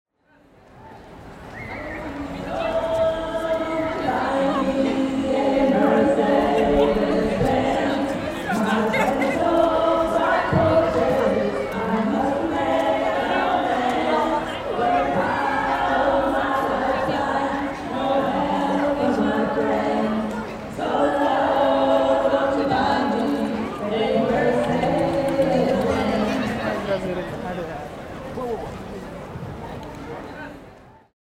Torgallmenningen-Ton.mp3